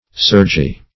Search Result for " surgy" : The Collaborative International Dictionary of English v.0.48: Surgy \Sur"gy\ (s[^u]r"j[y^]), a. Rising in surges or billows; full of surges; resembling surges in motion or appearance; swelling.